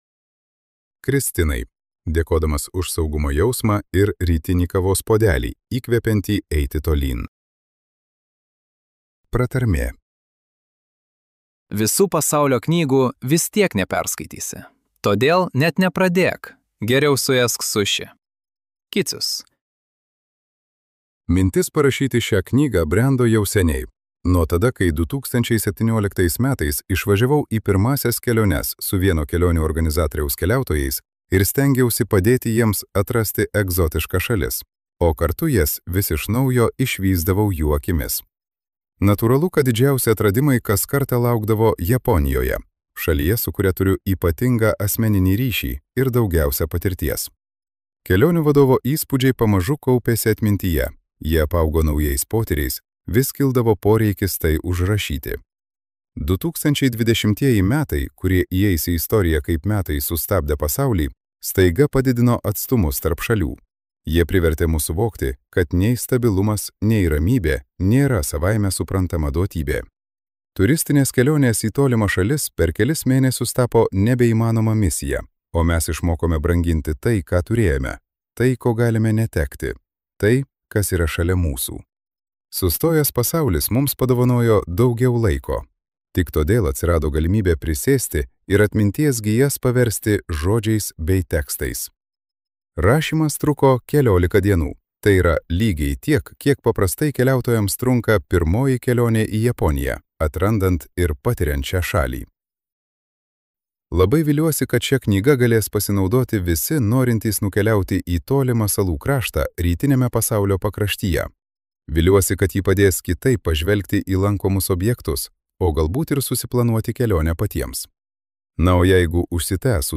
Septyni saulės veidai. Pirmoji pažintis su Japonija | Audioknygos | baltos lankos